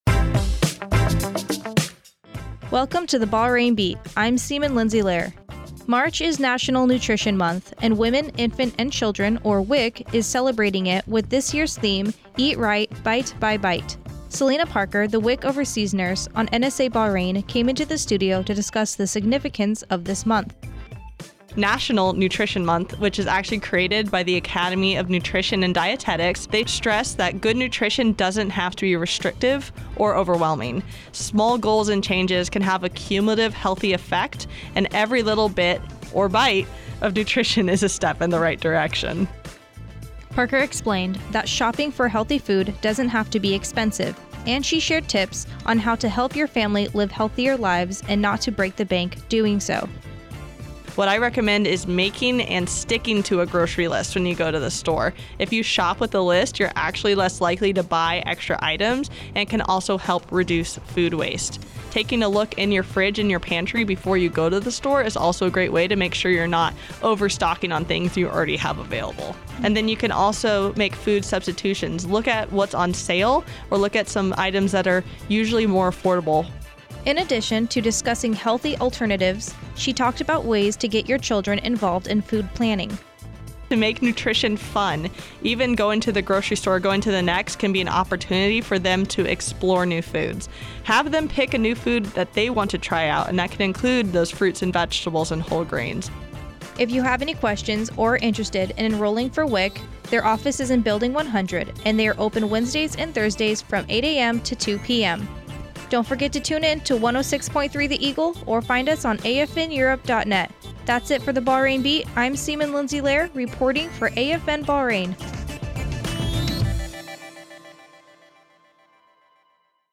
This audio file was produced from AFN Bahrain as its local newscast, which airs daily on 106.3 “The Eagle”.